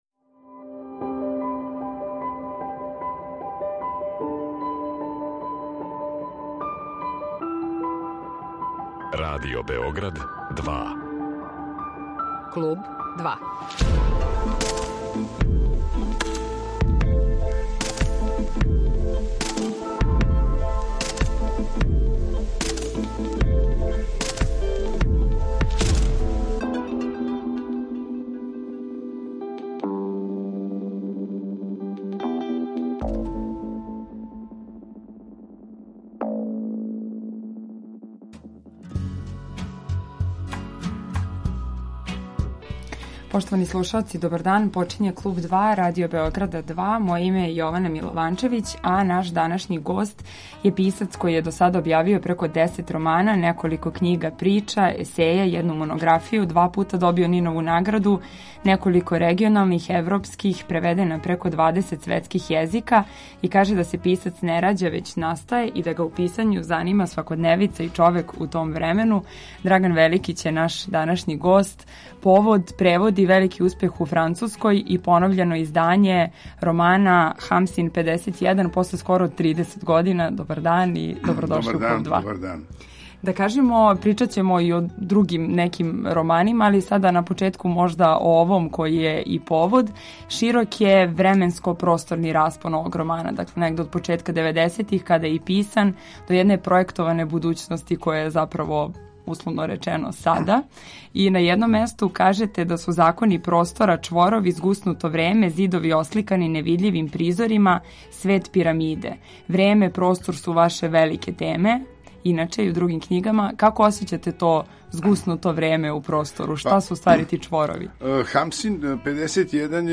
Гост Клуба 2 је Драган Великић, један од најбољих савремених српских писаца.